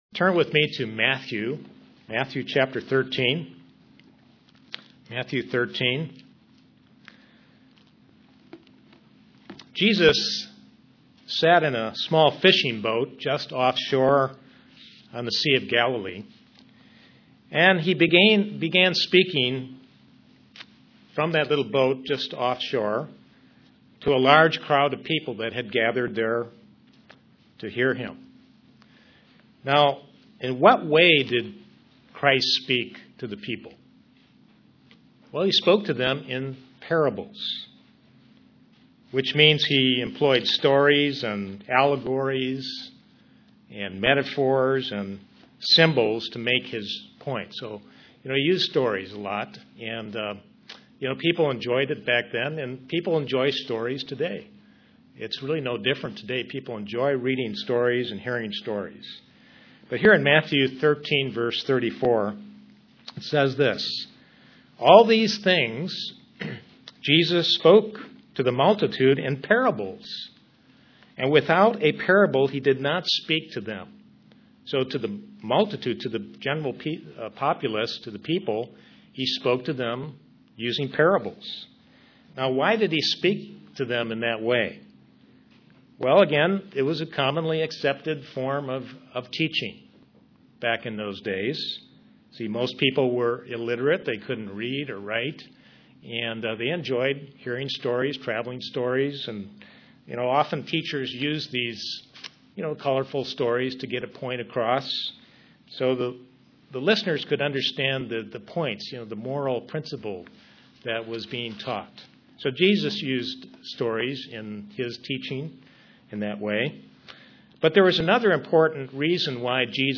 Given in Kingsport, TN
Print The parables are rich with various lessons for Christians UCG Sermon Studying the bible?